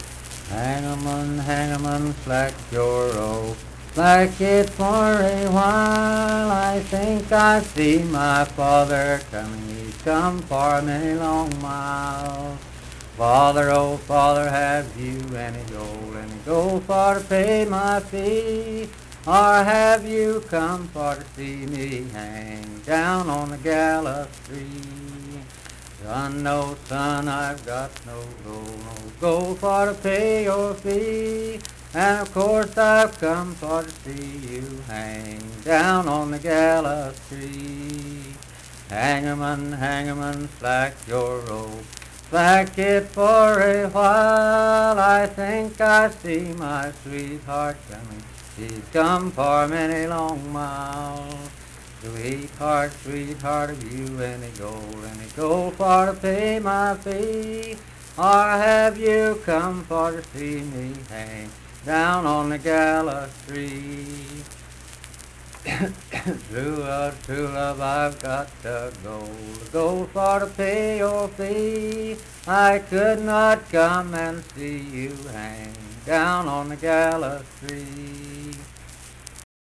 Unaccompanied vocal music performance
Performed in Kliny, Pendleton County, WV.
Voice (sung)